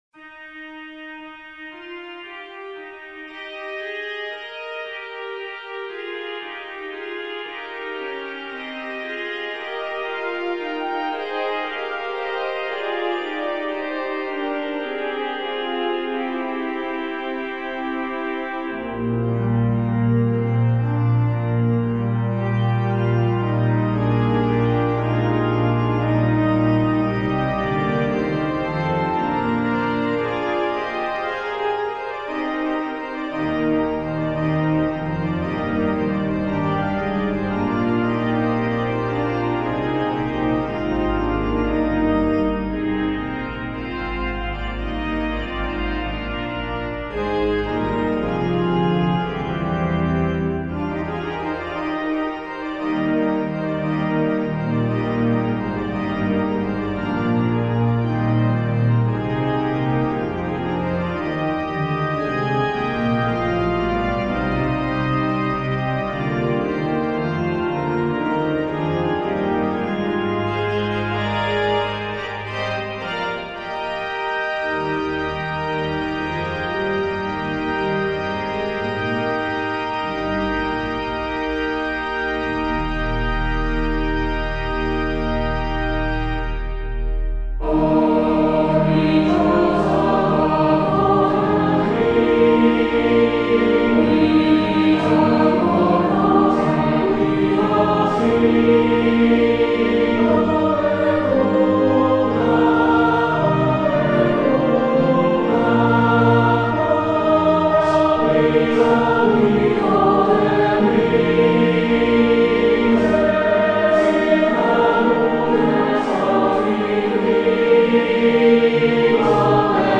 Descant to the hymn tune LASST UNS ERFREUEN - 'Ye watchers and ye holy ones,' 'All creatures of our God and king,' and 'That Easter day with joy was bright.'
Congregation, choir, and organ.